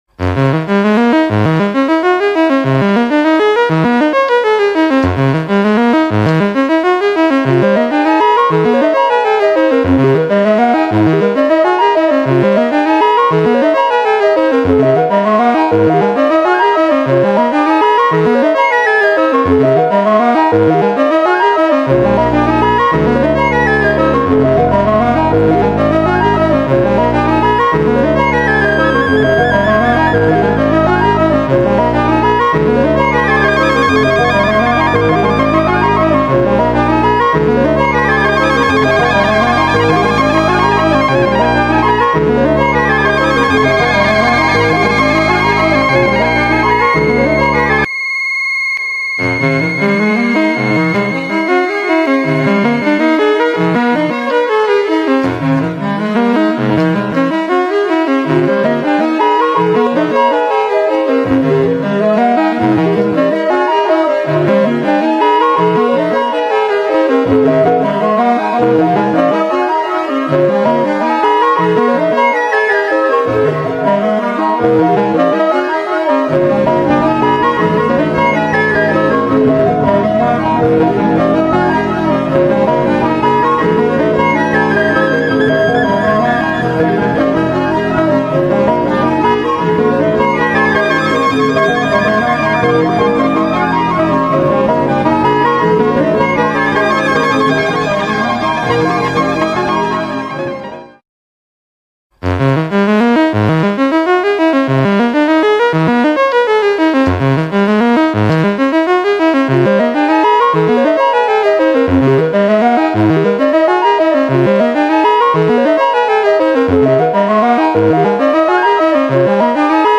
инди
мистические , без слов , инструментальные